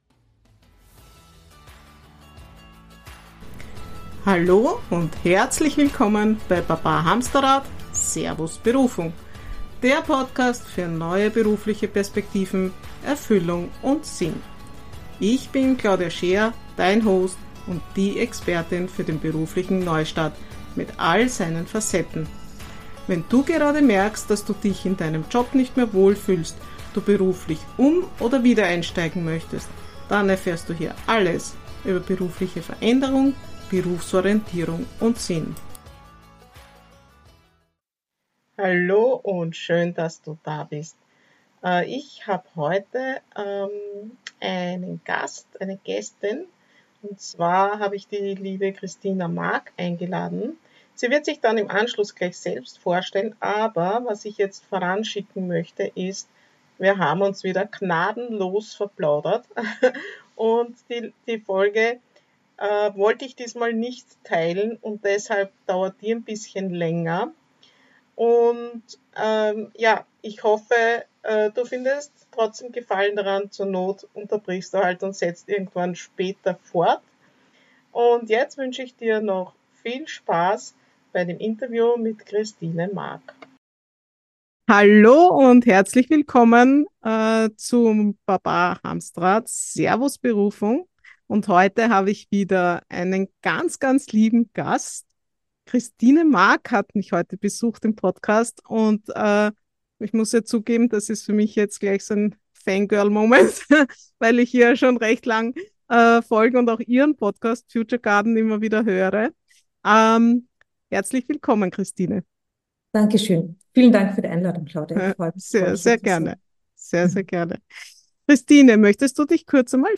In einem sehr inspirierenden Gespräch geht es um die Freude an der Arbeit und warum Work-Life-Balance dazu der falsche Ansatz ist.